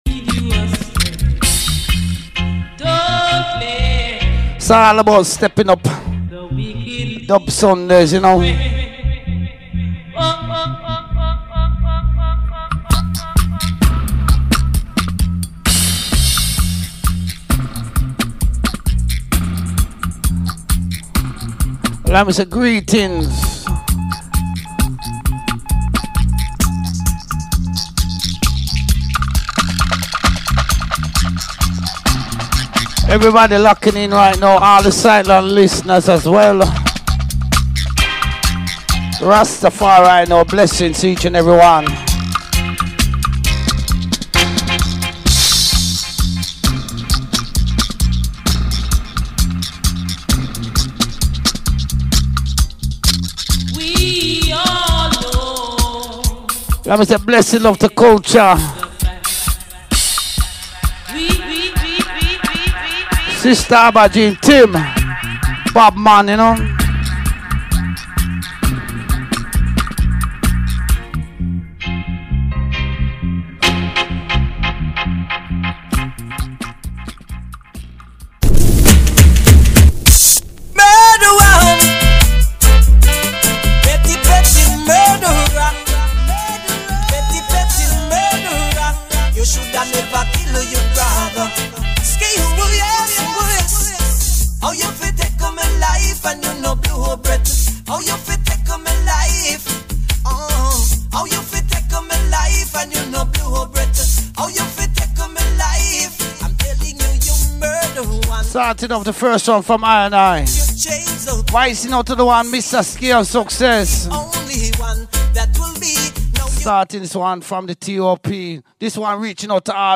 STEPPIN UP DUB SUNDAY FOUNDATION SELECTION .